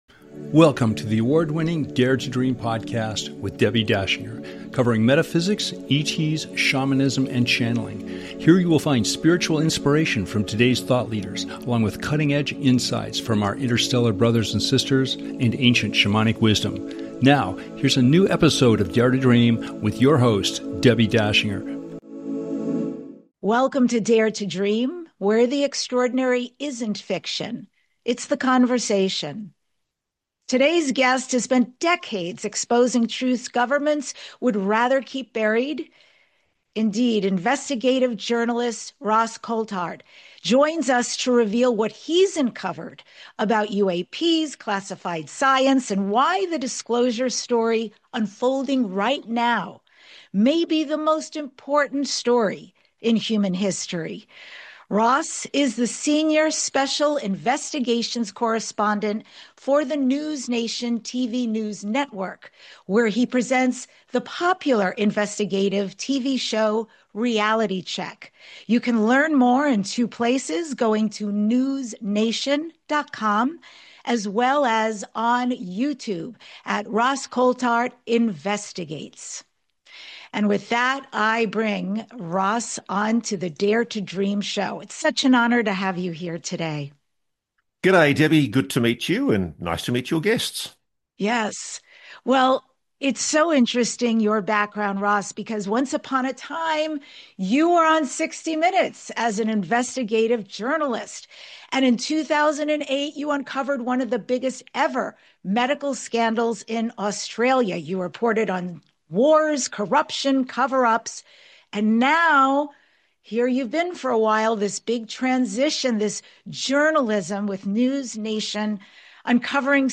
Talk Show Episode
Guest, ROSS COULTHART